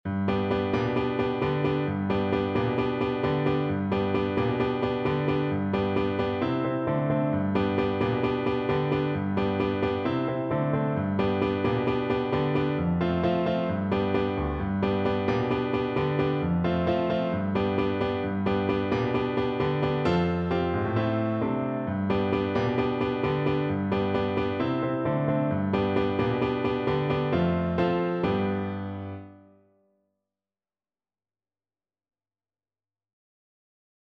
4/4 (View more 4/4 Music)
Fast =c.132
Caribbean Music for Flute